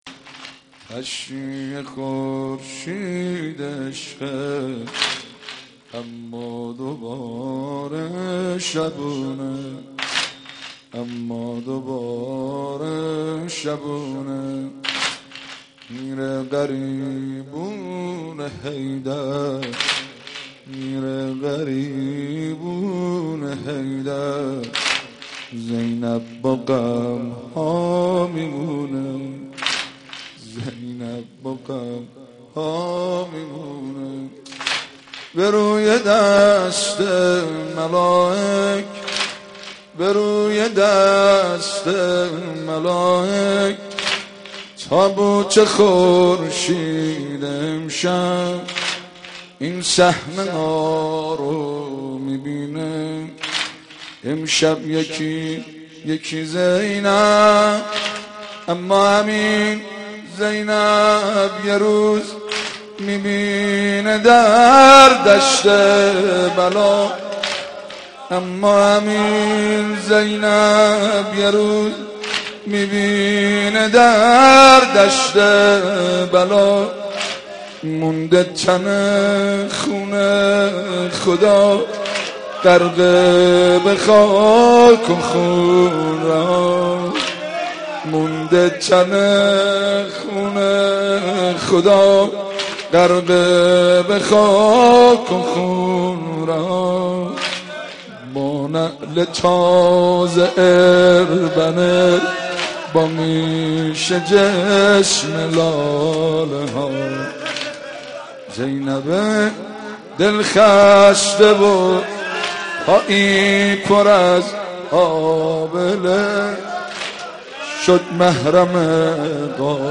رمضان 89 - سینه زنی 3
رمضان 89 - سینه زنی 3 خطیب: حاج مهدی سلحشور مدت زمان: 00:03:38